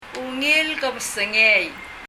[ŋil kəb(ə)sɛŋei]